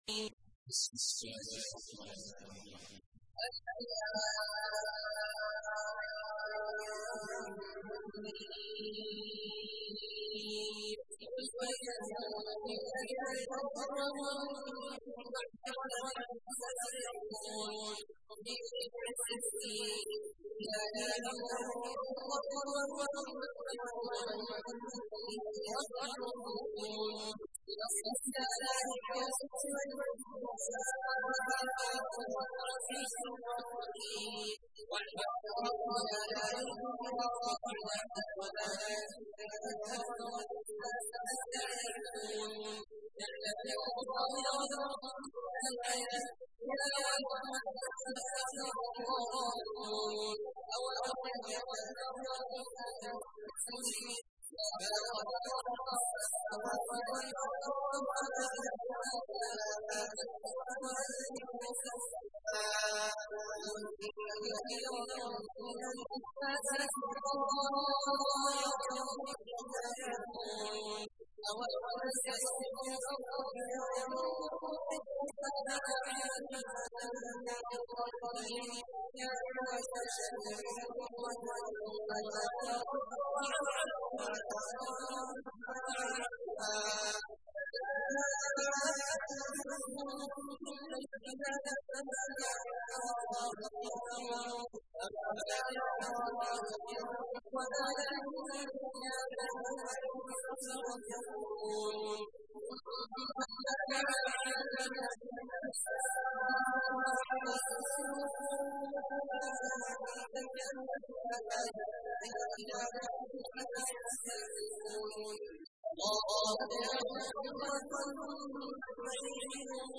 تحميل : 30. سورة الروم / القارئ عبد الله عواد الجهني / القرآن الكريم / موقع يا حسين